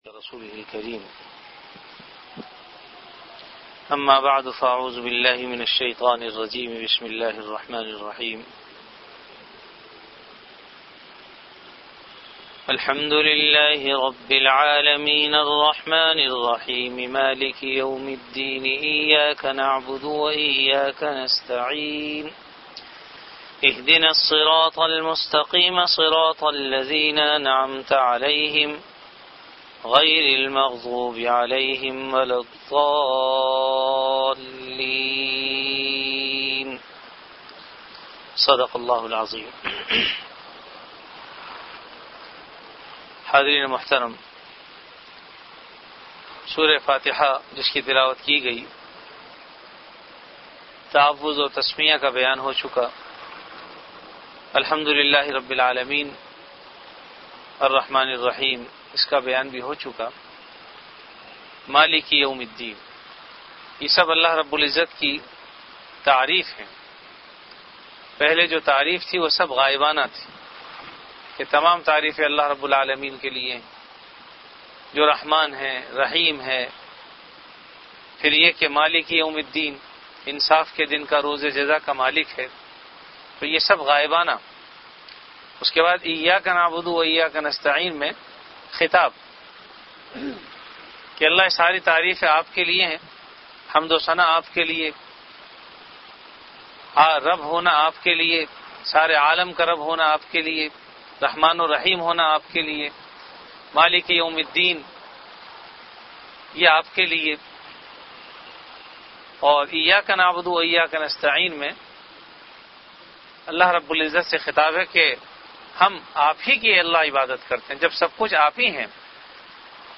Dars-e-quran · Jamia Masjid Bait-ul-Mukkaram, Karachi